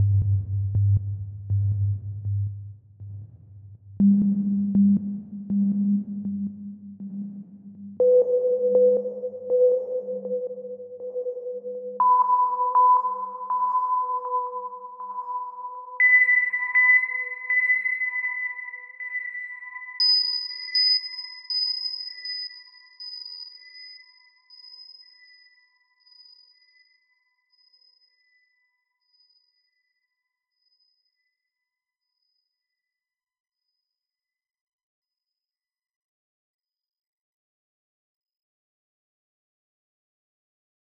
Atlas - STest1-PitchPulse-Left-100,200,500,1000,2000,5000.wav